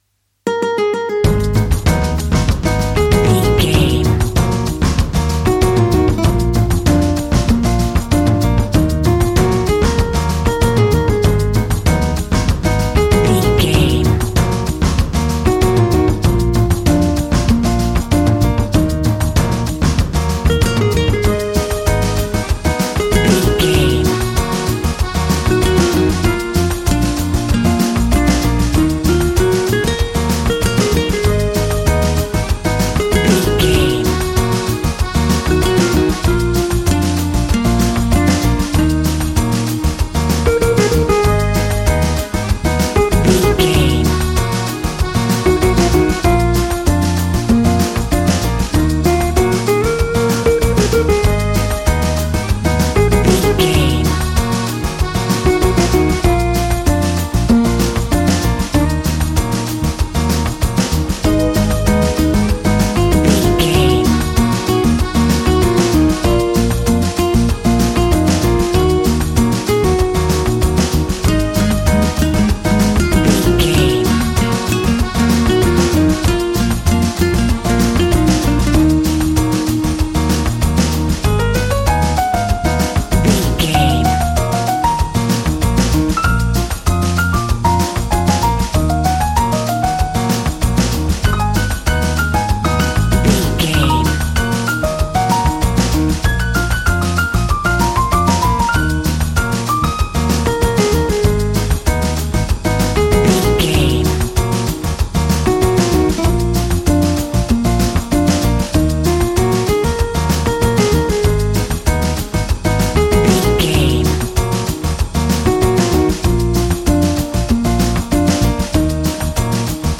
Aeolian/Minor
E♭
funky
energetic
romantic
percussion
electric guitar
acoustic guitar